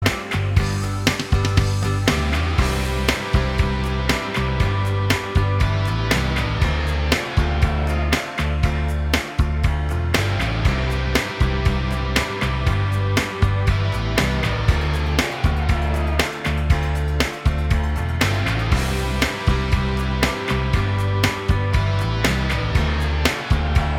Minus Main Guitars Pop (1990s) 4:03 Buy £1.50